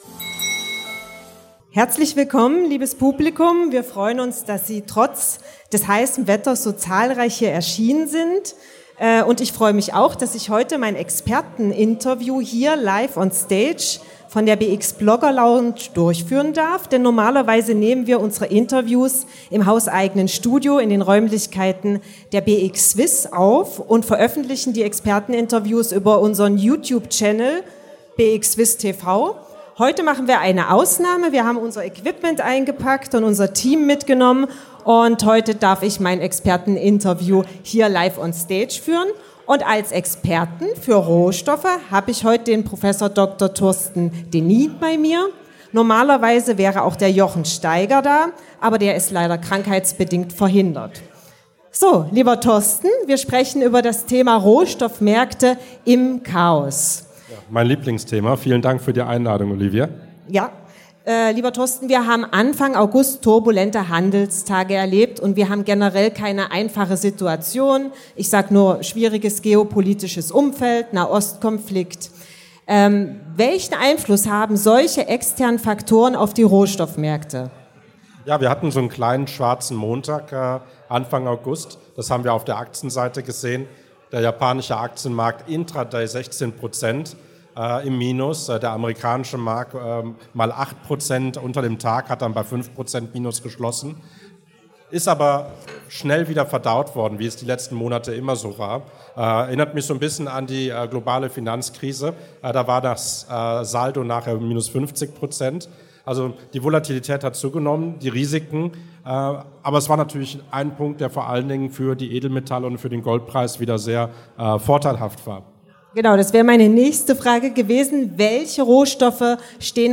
Beschreibung vor 1 Jahr Ein besonderes Highlight auf dem diesjährigen Börsentag Zürich war die BX Swiss Blogger-Lounge.
Interview